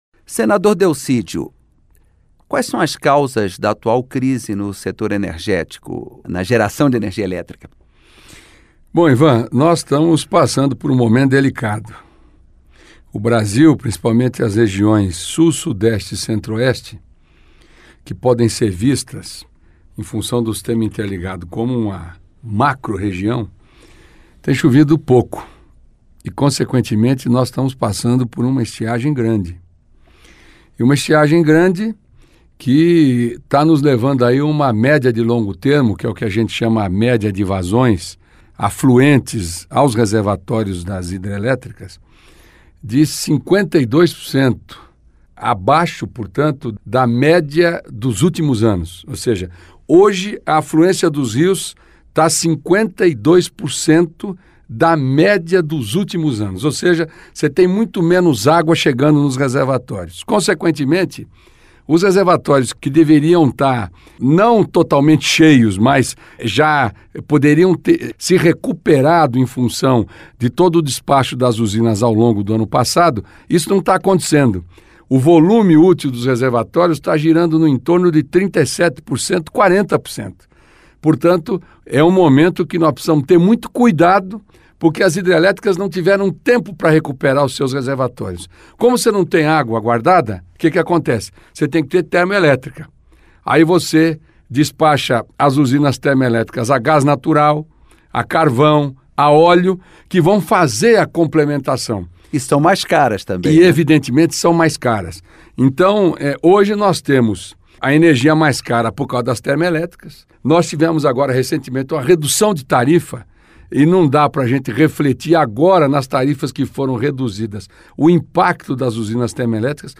Entrevista com o senador Delcídio do Amaral (PT-MS).